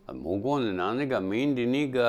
Type: Yes/no question
Final intonation: Falling
Location: Showamura/昭和村
Sex: Male